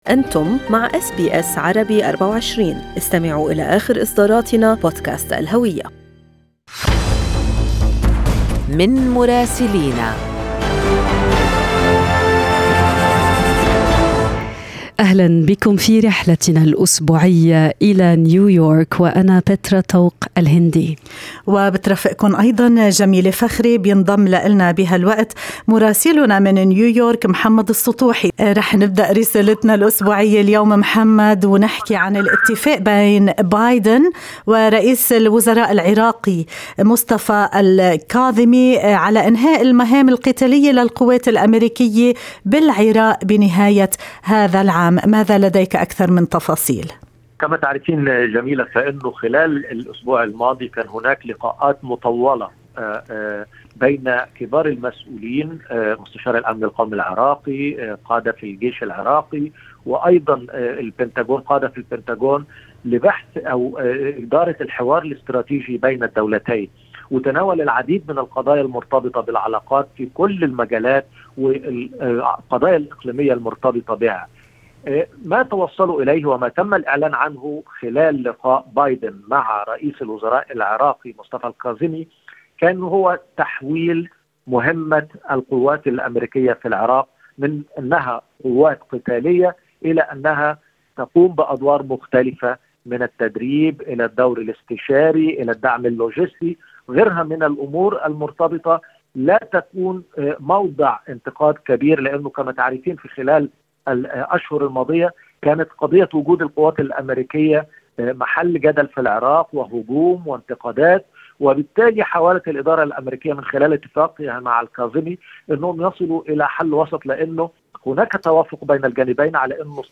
من مراسلينا: أخبار الولايات المتحدة الأمريكية 29/7/2021